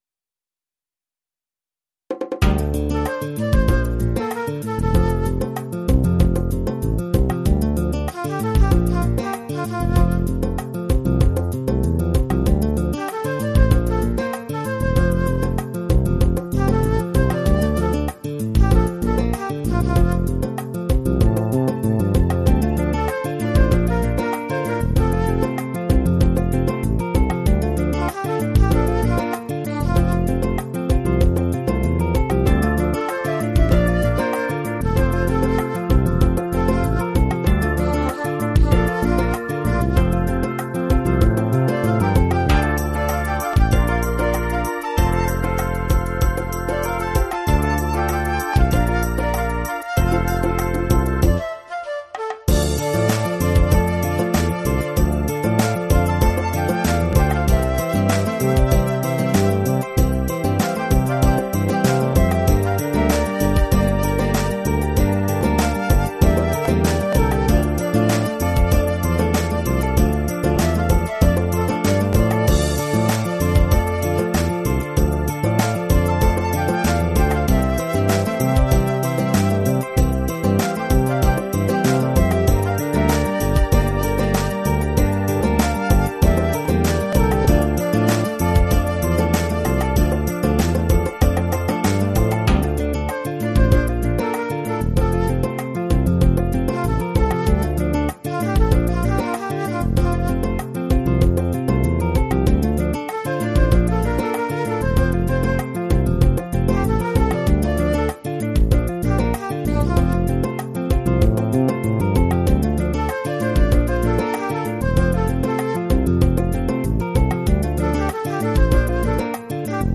version instrumentale multipistes